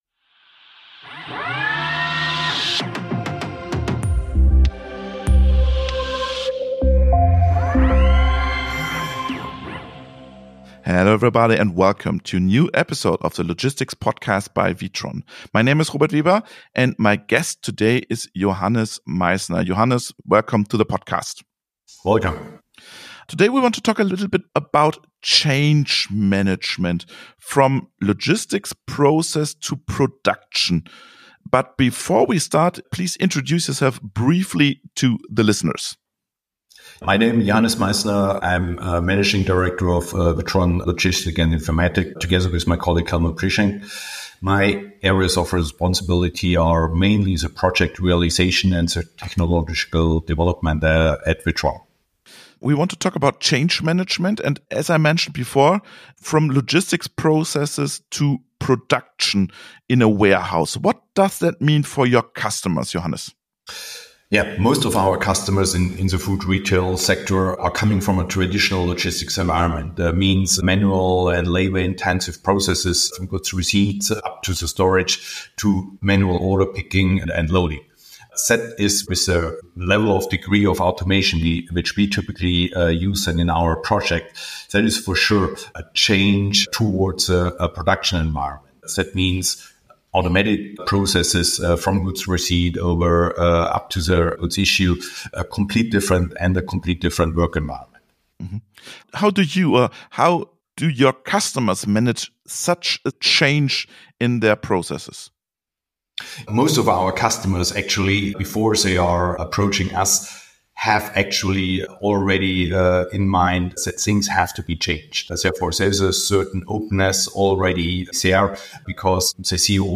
The two experts share their experiences from international projects and explain how companies can prepare their employees, IT and processes for these extensive changes.